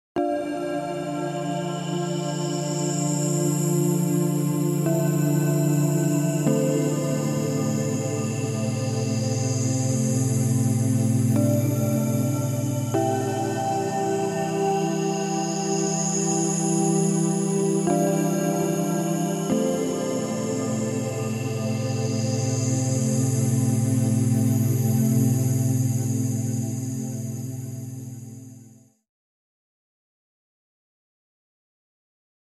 LFOAudio Hollywood Pads 是一款专业的弦乐合成器，它可以为你的音乐创作提供丰富而富有氛围的环绕声垫音效果。
它适合电影、原声、新世纪、环境等风格的音乐制作，可以为你的作品增添神秘而广阔的感觉。